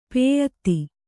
♪ pēyatti